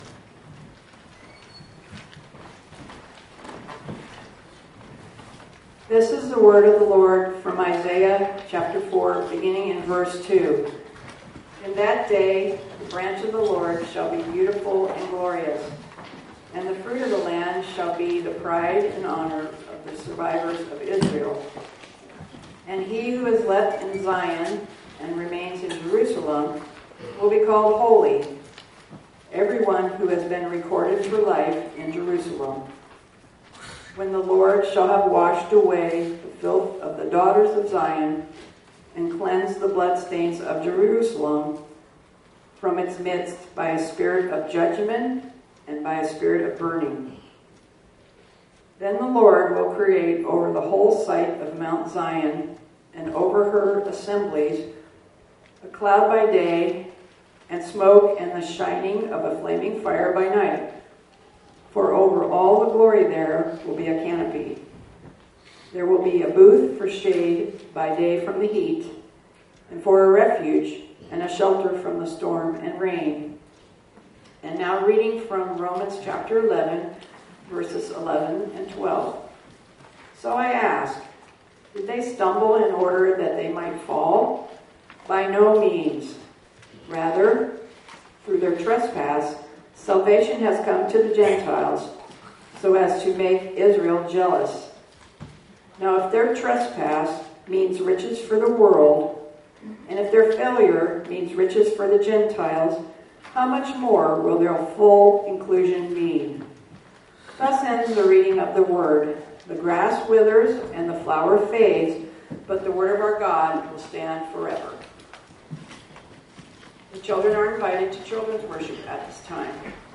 Isaiah4:2-6 &Romans11 The Branch is the Lord(6-1-25) | Sunrise Church McMinnville, OR